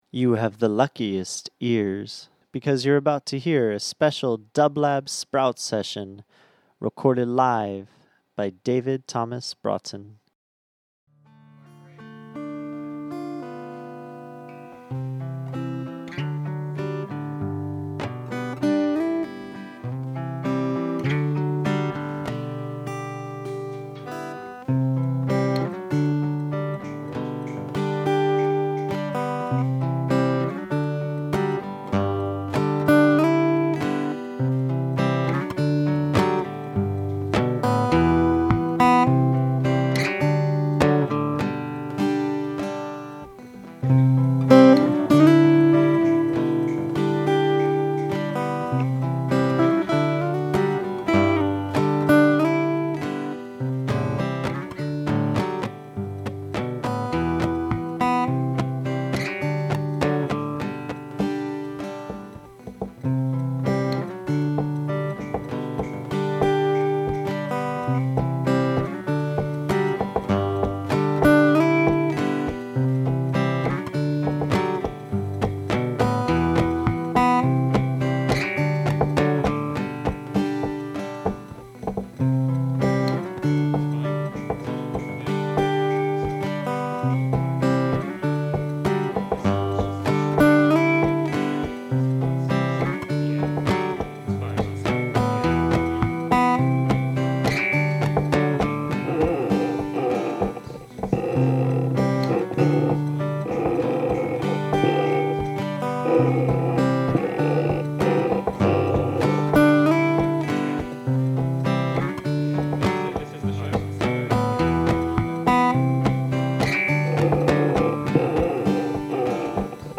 Electronic Folk